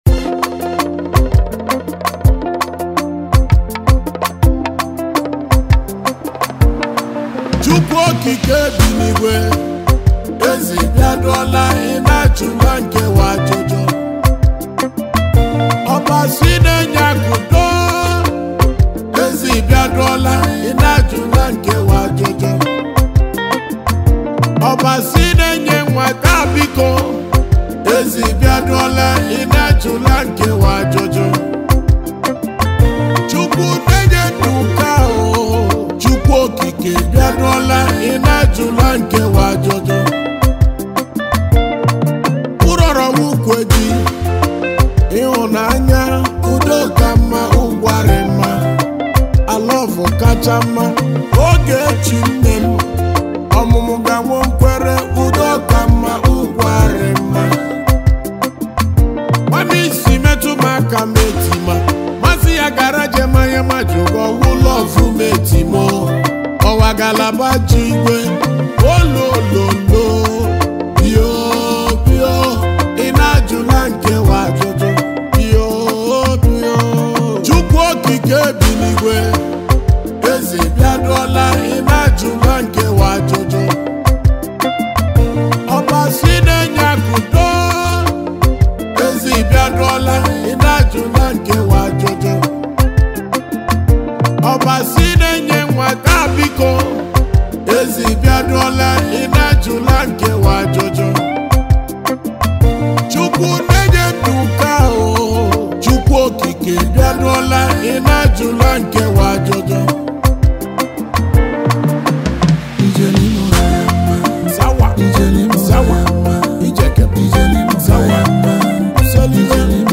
March 26, 2025 Publisher 01 Gospel 0